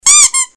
звонкие
Звук игрушки пищалки, предположительно это уточка